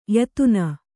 ♪ yatuna